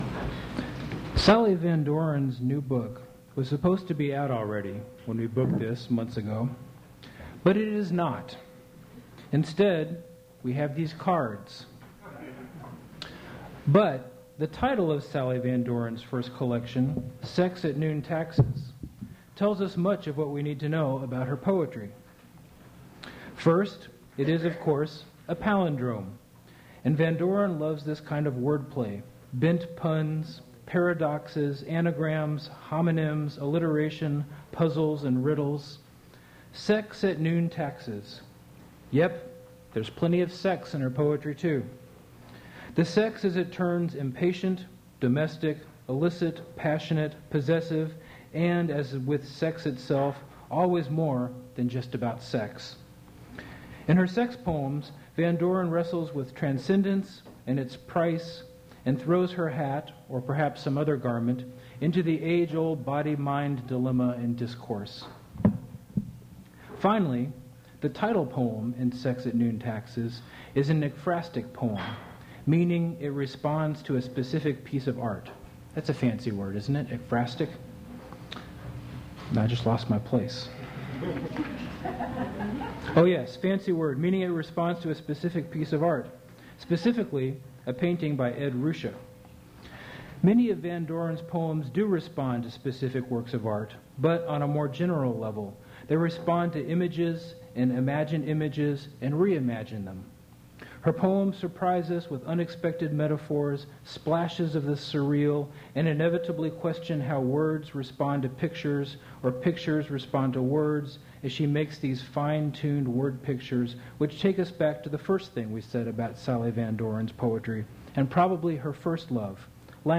Poetry reading
poetry reading at Duff's Restaurant